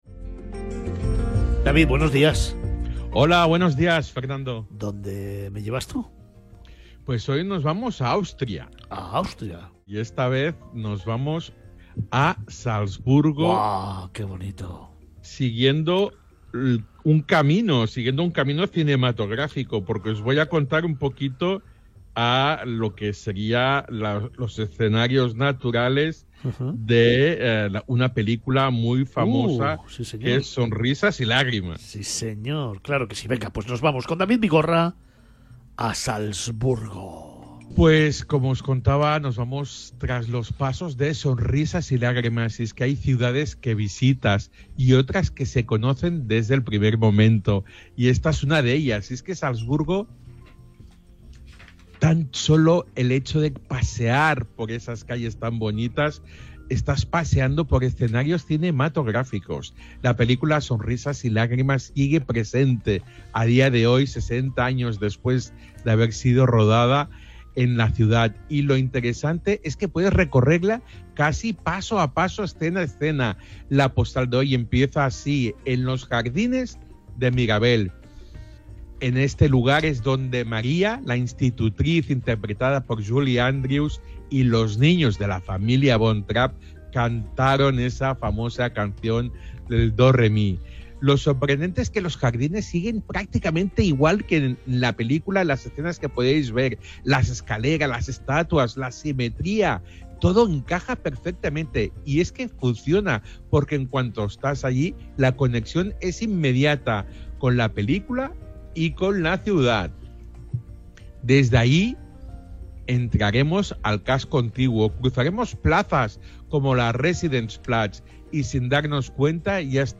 Bienvenidos a una nueva postal sonora de Miradas Viajeras.
Miradas Viajeras se emite en Capital Radio, una emisora centrada en la información económica y empresarial.